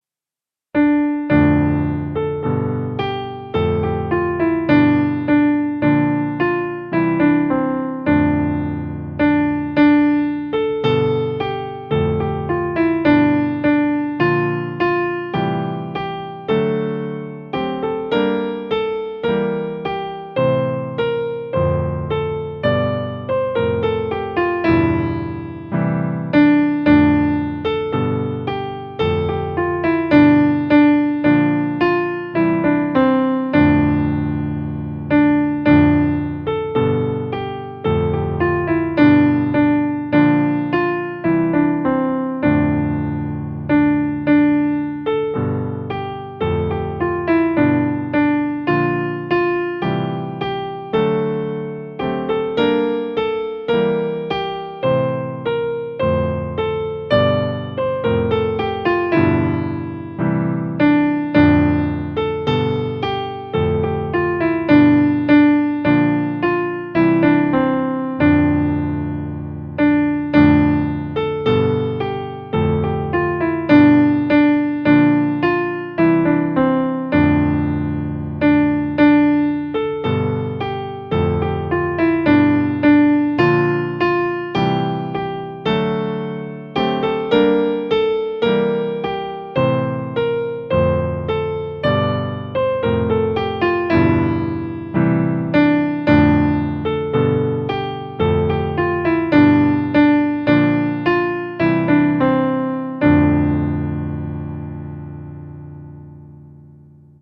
a-sower-came-from-ancient-hills-seachrist-piano.mp3